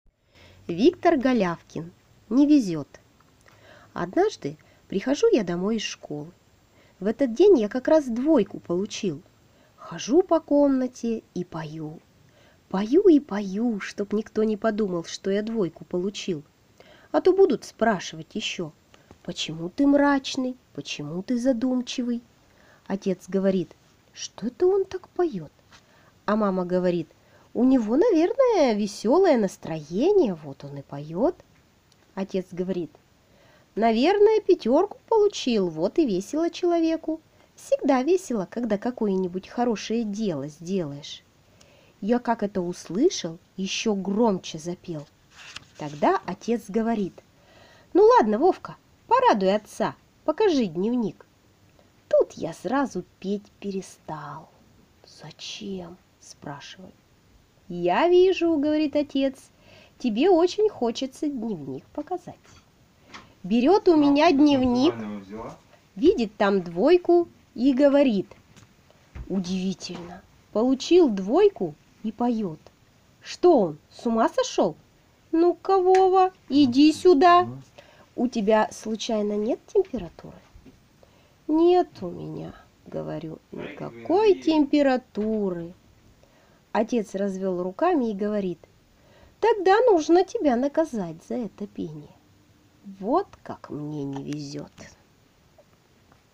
Аудиорассказ «Не везёт»